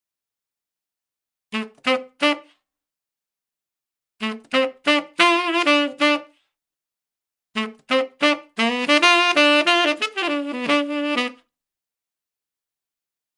新款次中音萨克斯D小调" Dm 90bpm过快4bar
描述：用Audio Technica AT2020麦克风在运行Logic Express 8的Macbook上录音（我喜欢我的Macbook，比我用了这么多年的windows垃圾好多了！）。 除了正常化之外，没有添加任何效果。 为了提高速度，转换为MP3，但使用了最高质量的设置，所以应该是几乎无损的
标签： 构造 放克 髋关节 片断 萨克斯 男高音
声道立体声